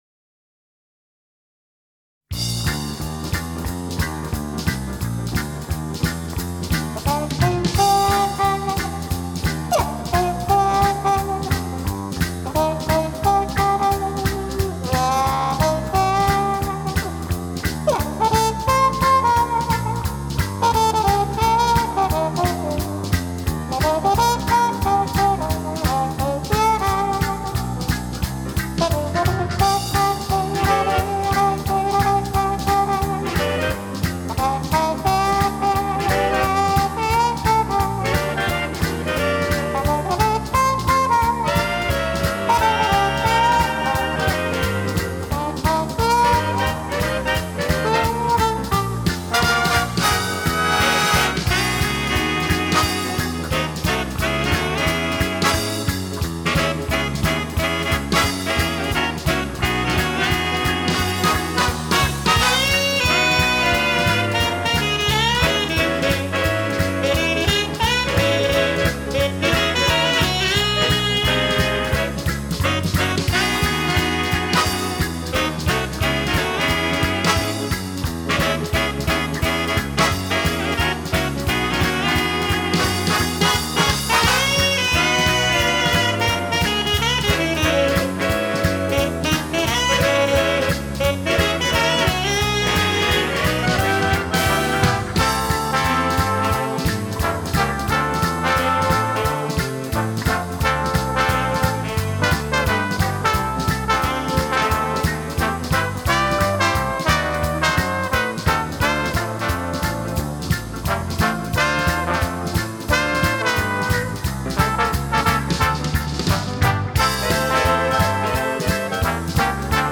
Более сочное, более звучное исполнение.